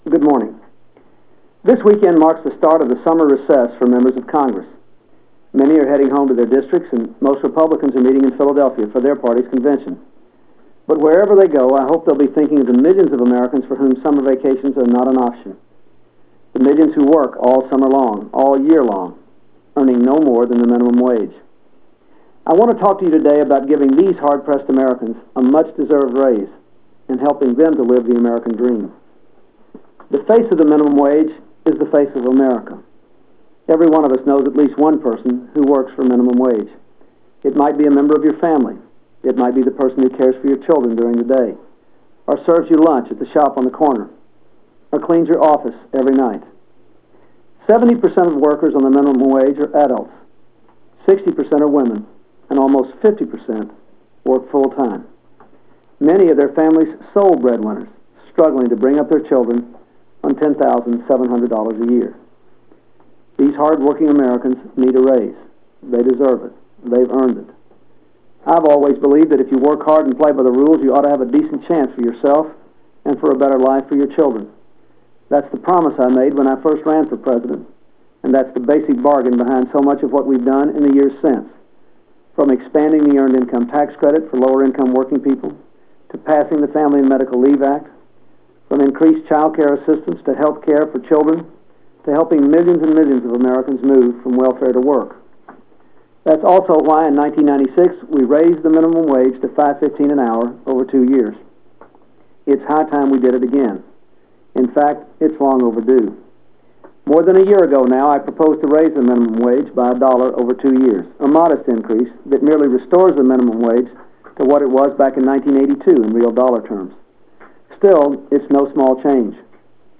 Radio Address of the President - July 29, 2000
Providence, Rhode Island